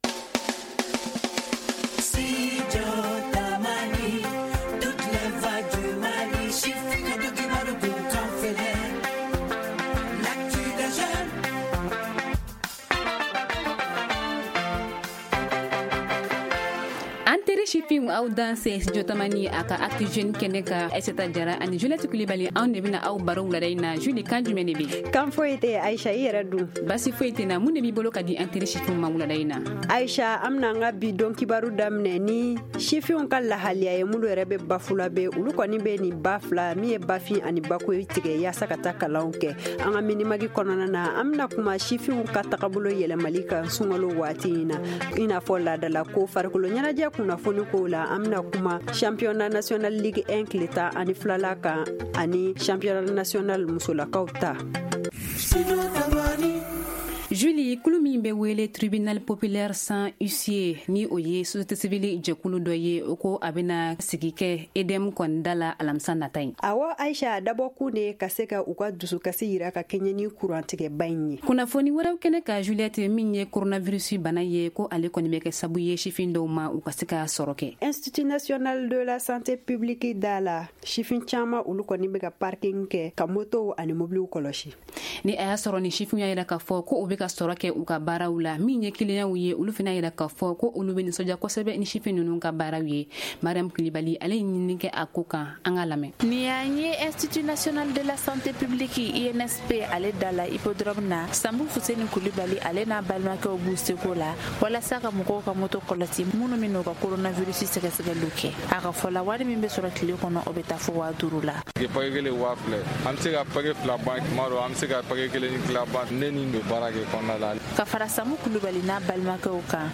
Ci-dessous, écoutez le développement de ces titres dans nos journaux en français et en langues nationales :